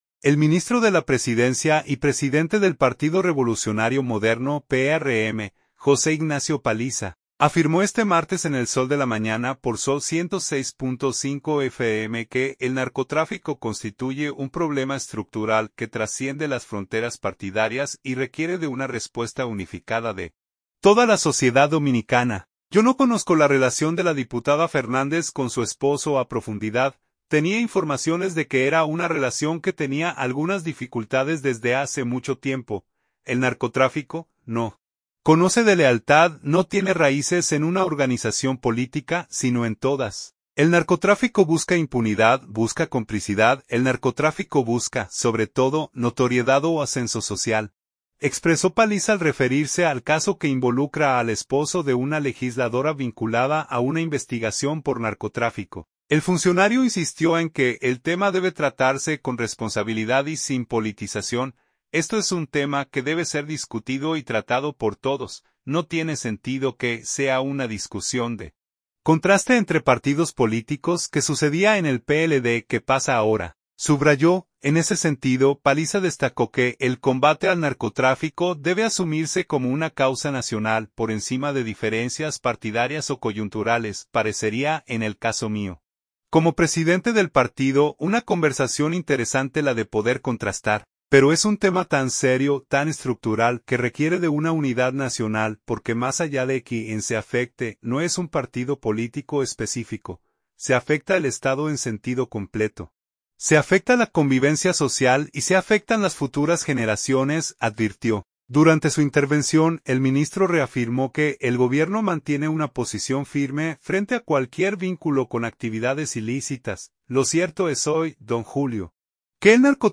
El ministro de la Presidencia y presidente del Partido Revolucionario Moderno (PRM), José Ignacio Paliza, afirmó este martes en El Sol de la Mañana por Zol 106.5 FM que el narcotráfico constituye un problema estructural que trasciende las fronteras partidarias y requiere de una respuesta unificada de toda la sociedad dominicana.